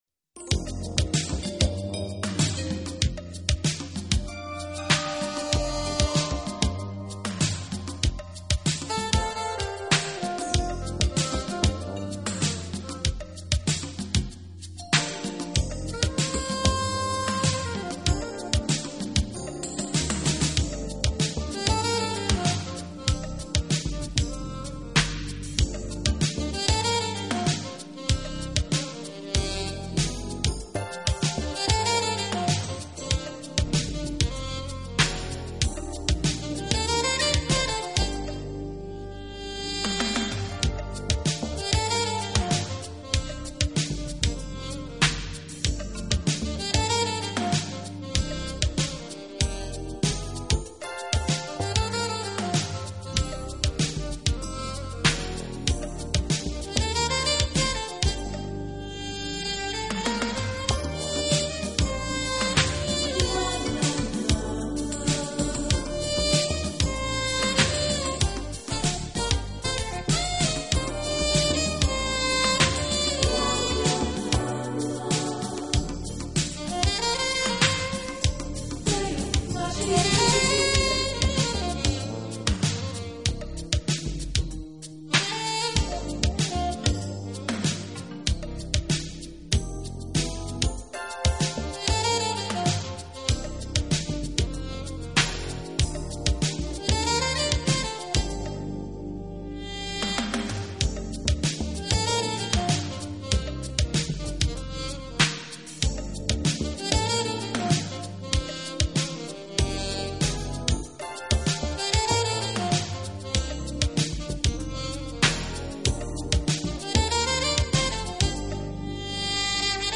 【爵士萨克斯】
音乐类型：爵士樂
人的感觉是深沉而平静，轻柔而忧伤，奇妙而富有感情。